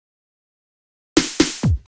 Fill 128 BPM (3).wav